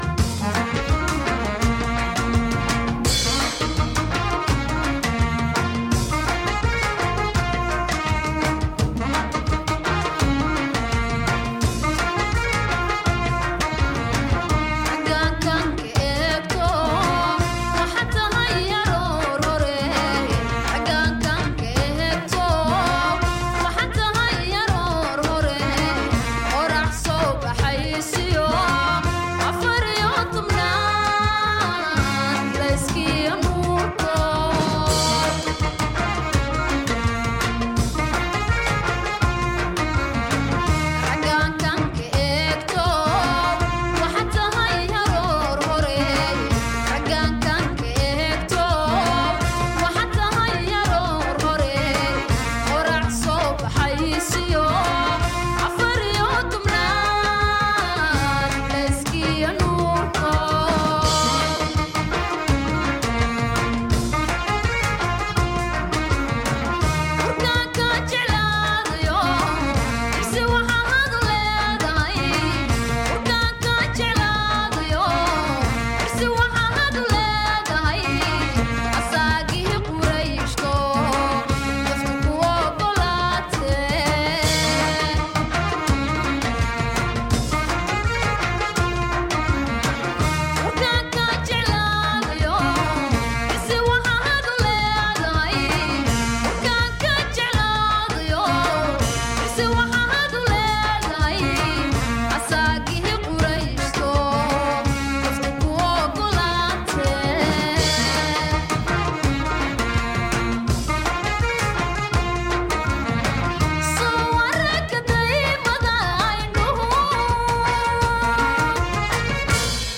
Incrível jornada gravada em três dias
Sobra, e em quantidade abundante, a expressão emotiva.